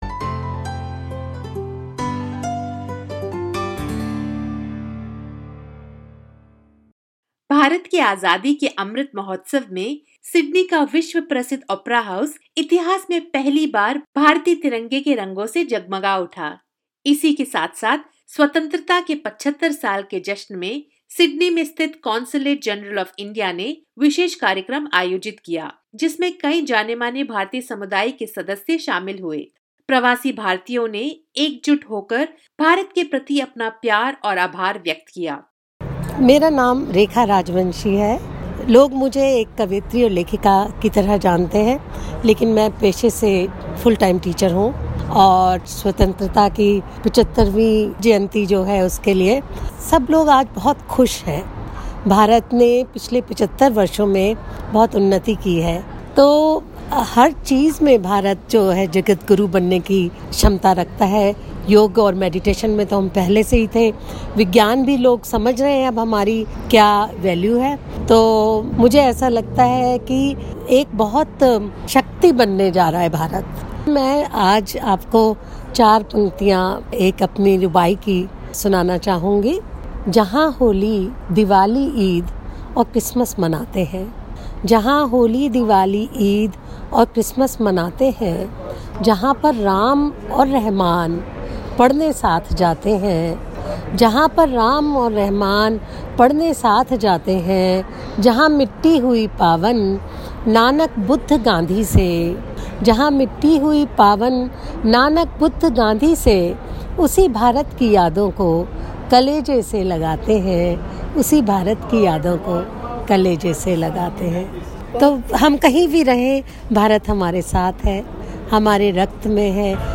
New South Wales Minister for Multiculturalism, Mark Coure says that the tricolour display at Sydney’s Opera house symbolises the long-standing friendship between Australia and India. As the two nations come together to commemorate the 75th anniversary of Indian independence, SBS Hindi speaks to community leaders who made an appearance at Azadi Ka Amrit Mahotsav event to show their support and celebrate with the community.